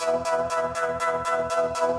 SaS_MovingPad02_120-E.wav